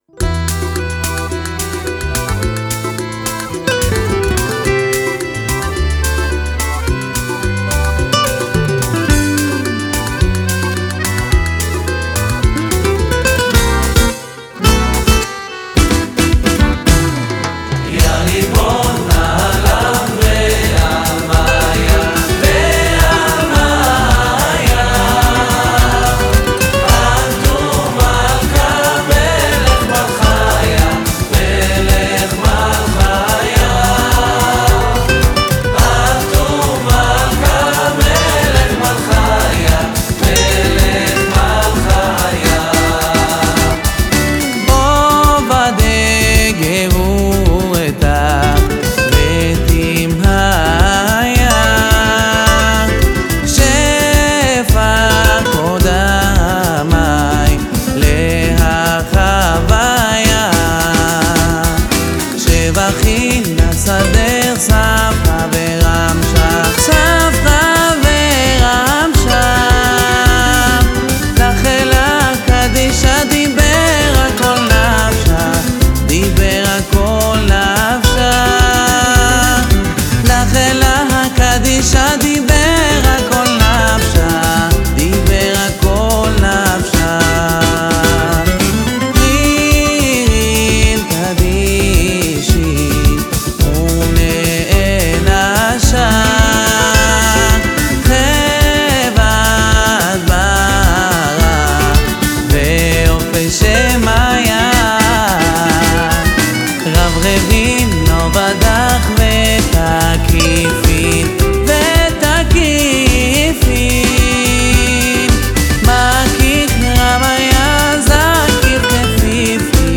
שירי שבת